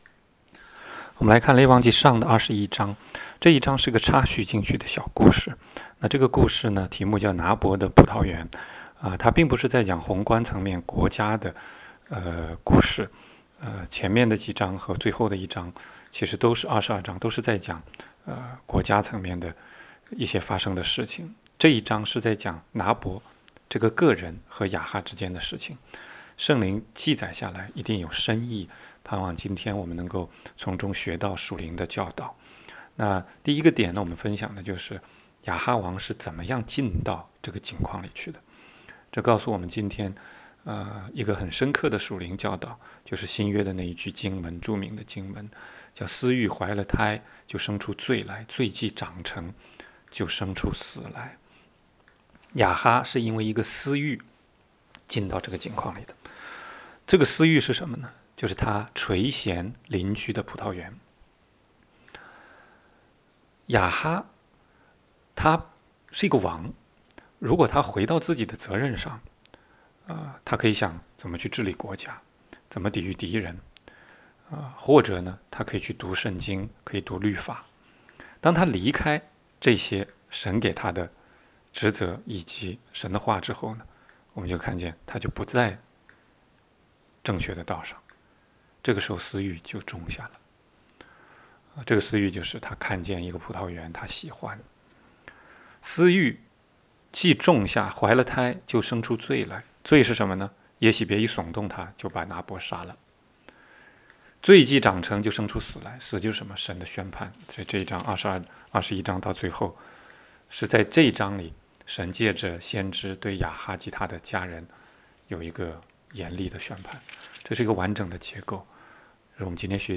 16街讲道录音 - 每日读经-《列王纪上》21章